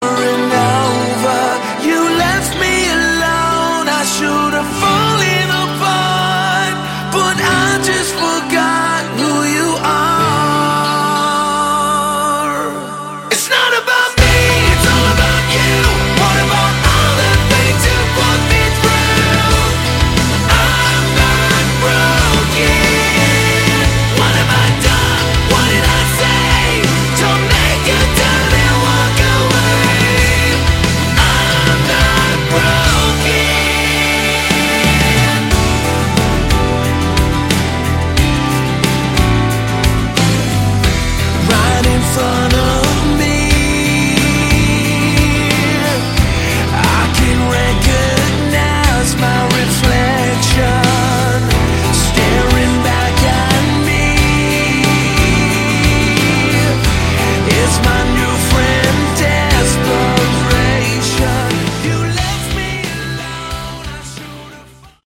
Category: Melodic Rock
lead & backing vocals
guitars, bass
drums, keyboards